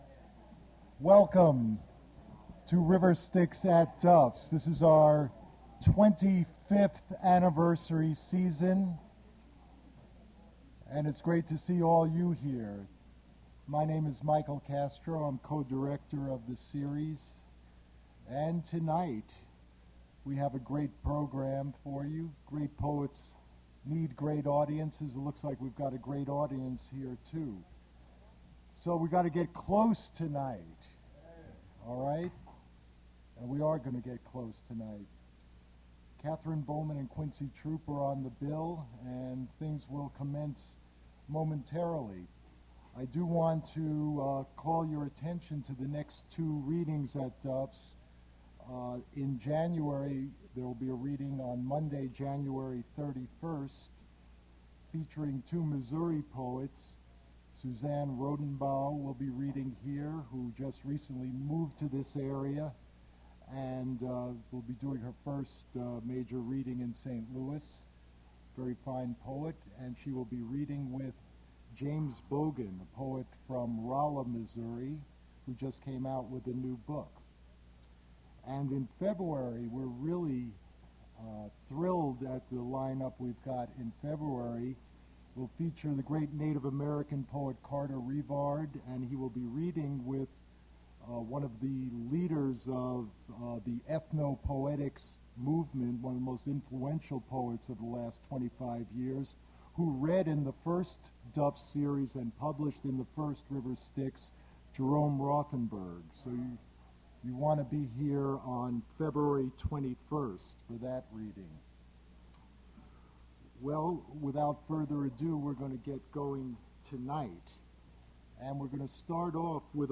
Poetry reading
poetry reading at Duff's Restaurant
mp3 edited access file was created from unedited access file which was sourced from preservation WAV file that was generated from original audio cassette.
Questionable audio quality - quiet, muffled